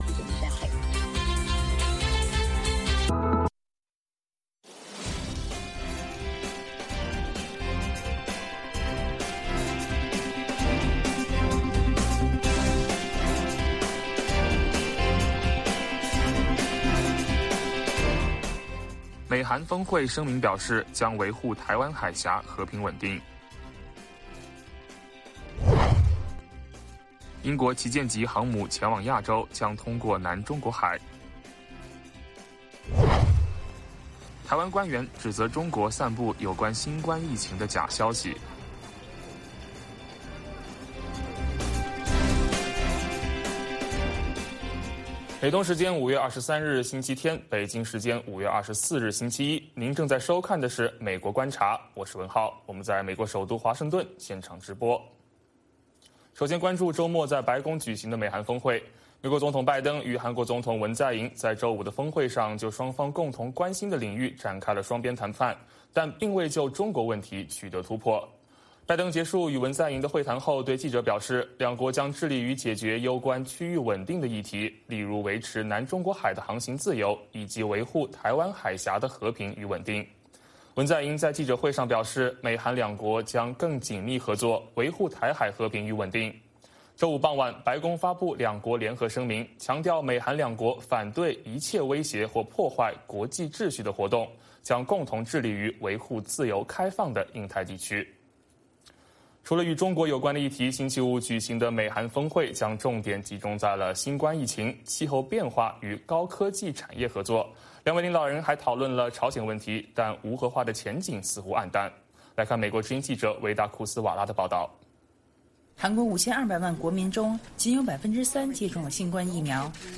北京时间早上6点广播节目，电视、广播同步播出VOA卫视美国观察。 “VOA卫视 美国观察”掌握美国最重要的消息，深入解读美国选举，政治，经济，外交，人文，美中关系等全方位话题。节目邀请重量级嘉宾参与讨论。